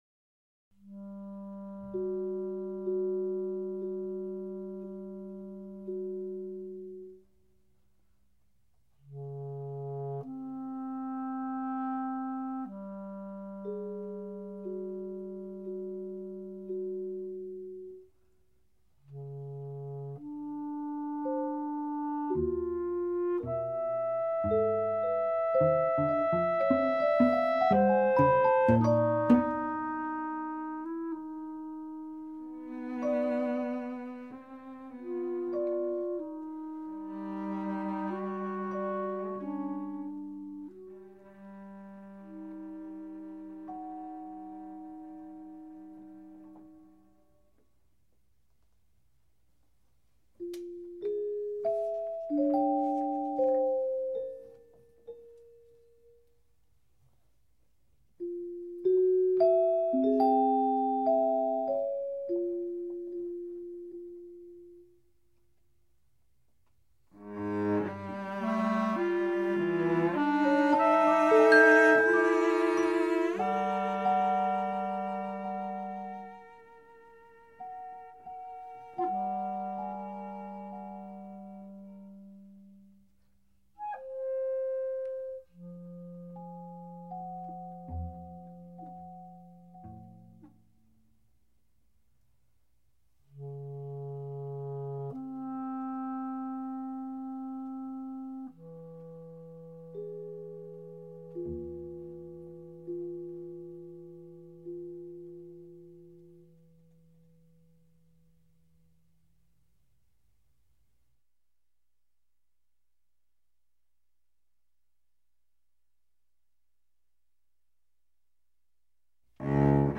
Three short movements for clarinet, celeste, and cello in an atonal style. The outer movements are dreamlike, while the central movement is a strange, grotesque dance.
Trio-for-Clarinet-Cello-and-Celeste.mp3